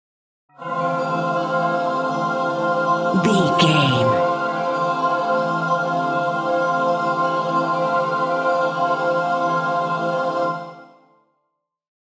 Angels Choir
Sound Effects
Atonal
dreamy
bright
calm